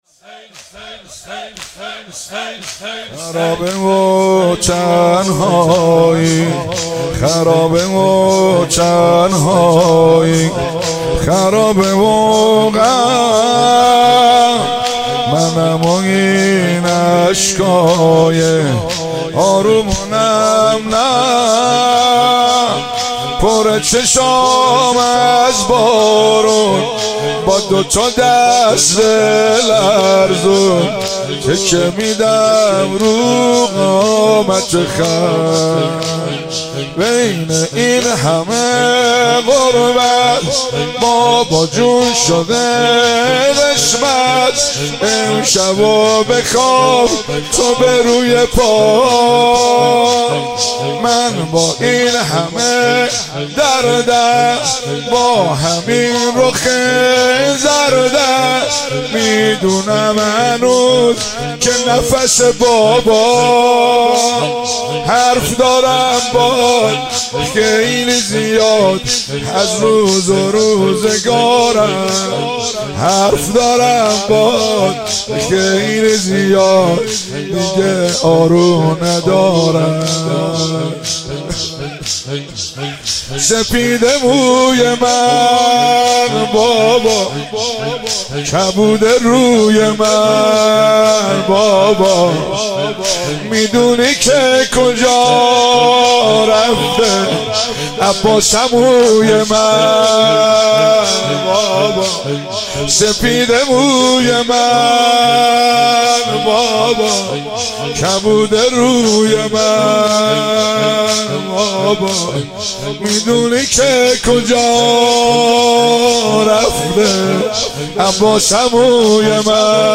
4 آبان 96 - هیئت فاطمیون - زمینه - خرابه و تنهایی